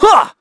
Evan-Vox_Attack3.wav